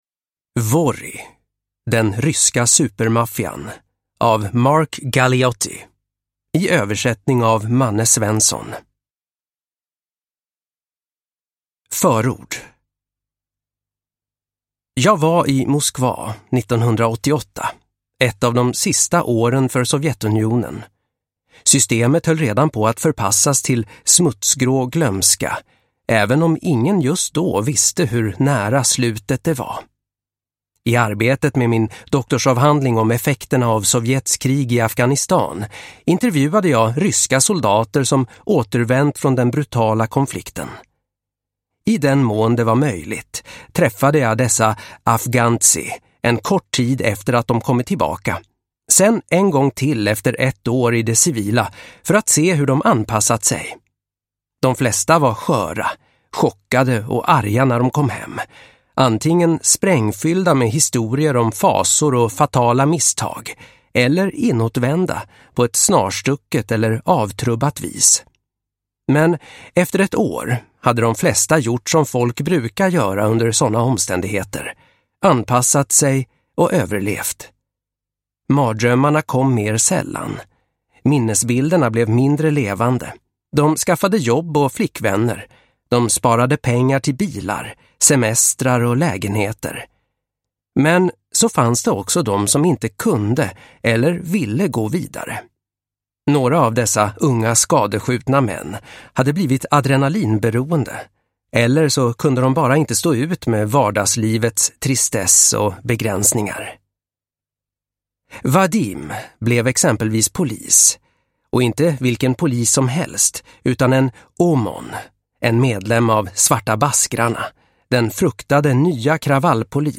Vory : den ryska supermaffian – Ljudbok